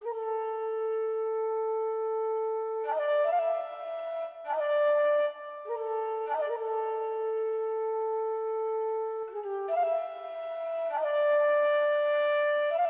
Navy_Flute.wav